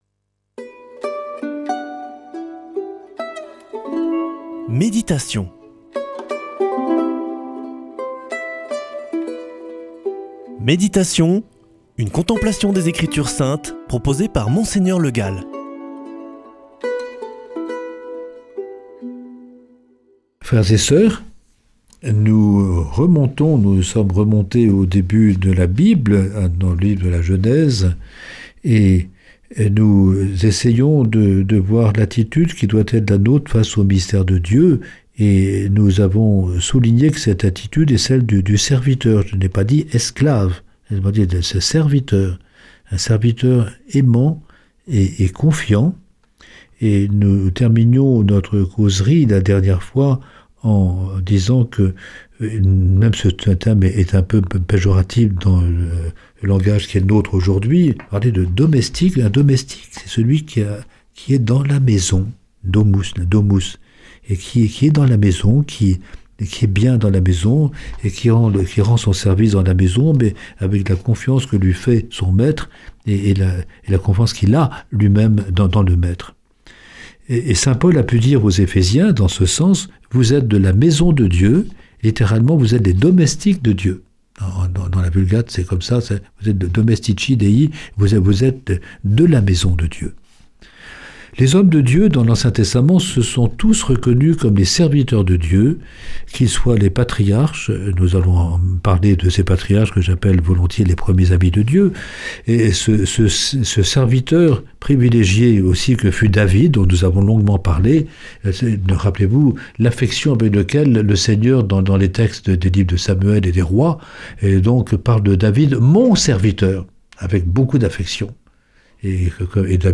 Méditation avec Mgr Le Gall